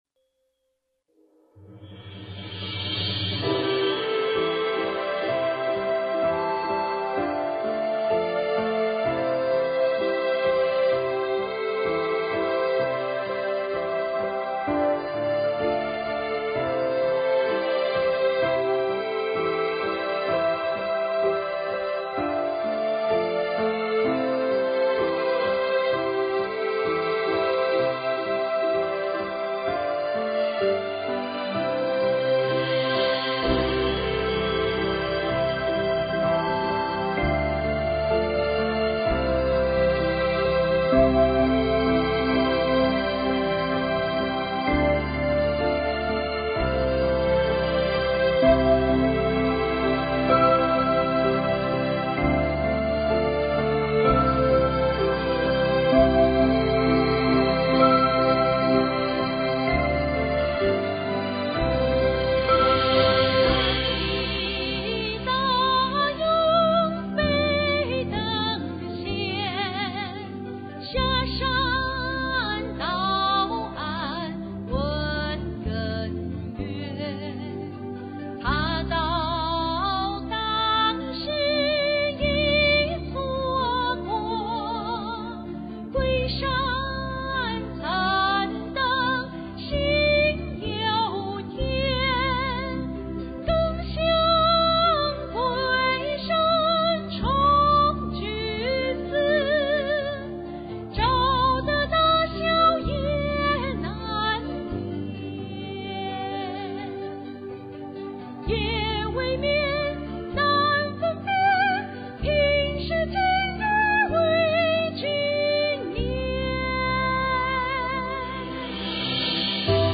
是用黄梅调风格演唱的